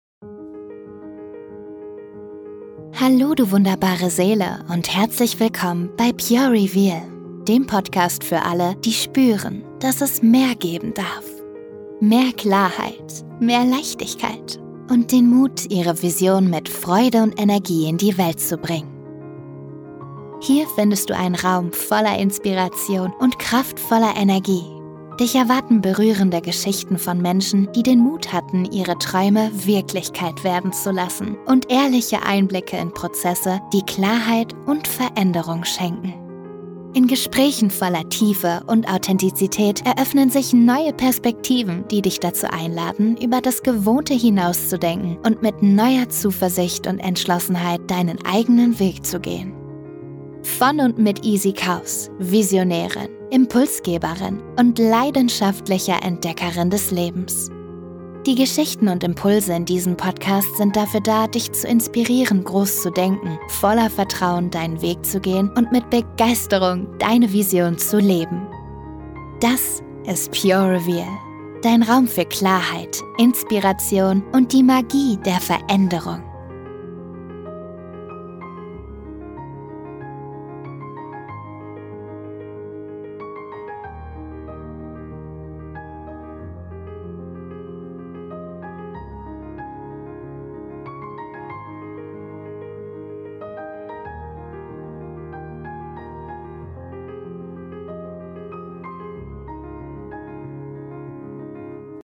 Podcast: Intro, Outro
Pure-Reveal-Intro-mit-Musik-Laustaerke-25dB.mp3